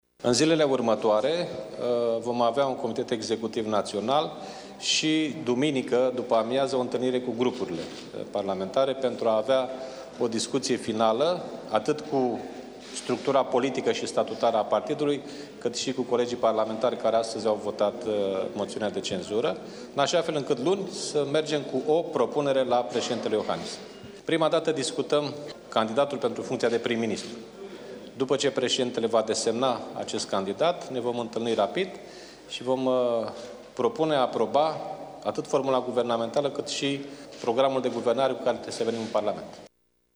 După ce moțiunea de cenzură inițiată de PSD împotriva guvernului Grindeanu a trecut, pentru social-democrați va urma ședința Comitetului Executiv în care vor fi discutate nominalizările pentru funcția de premier, a explicat președintele social democraților, Liviu Dragnea: